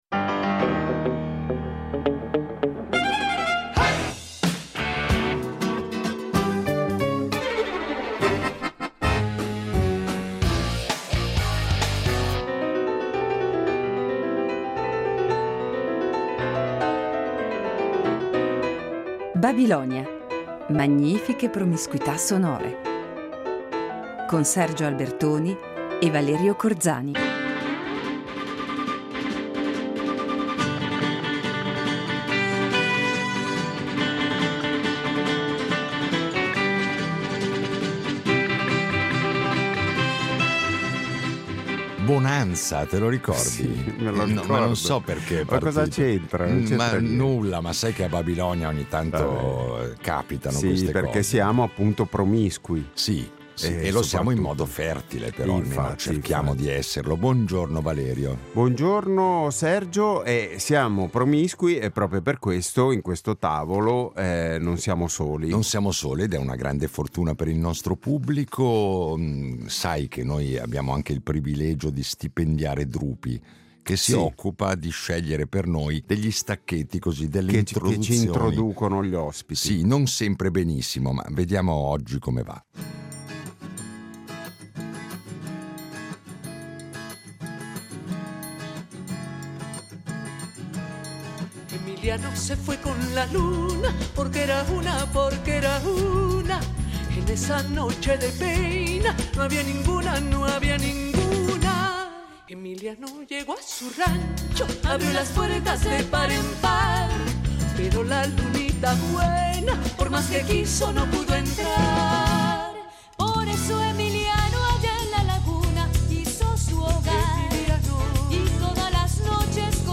Incontro con il pianista e compositore